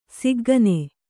♪ siggane